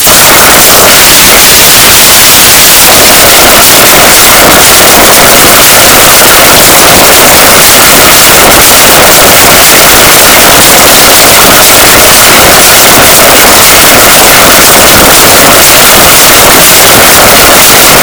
World's Loudest Sound!!!!